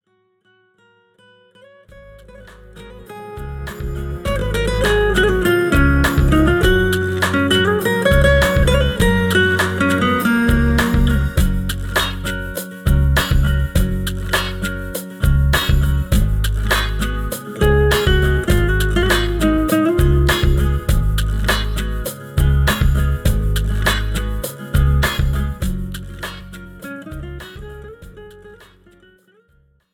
This is an instrumental backing track cover.
• Key – F
• Without Backing Vocals
• No Fade